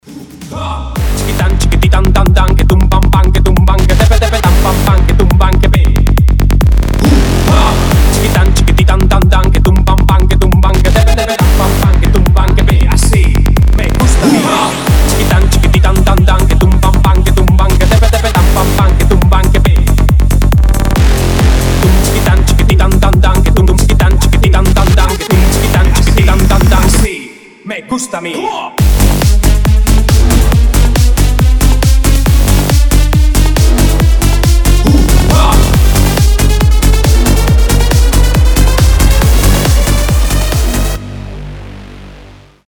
клубные
psy-trance
edm , быстрые